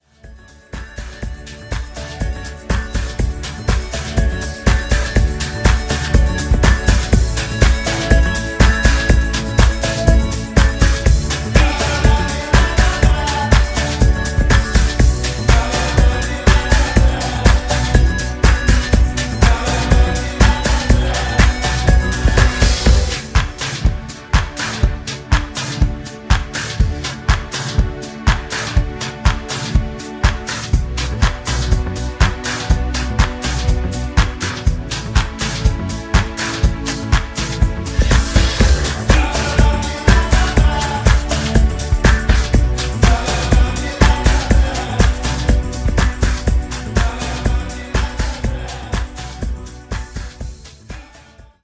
(avec choeurs)